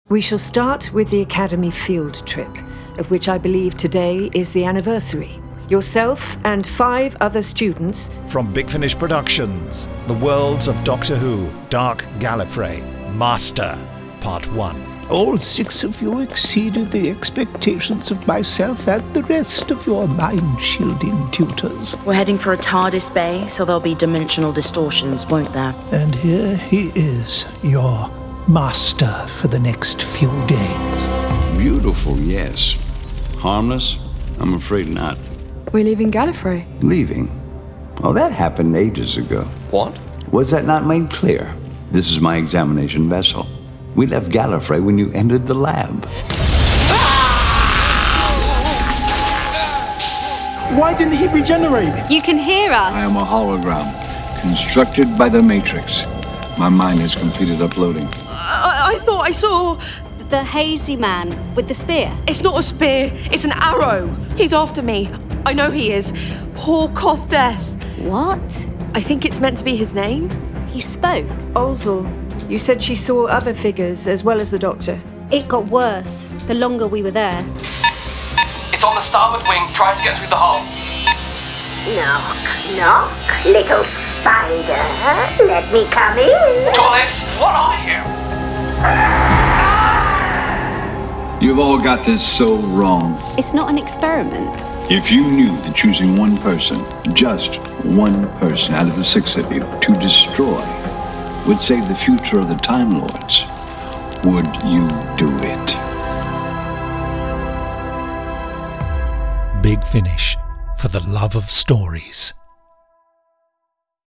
Starring Eric Roberts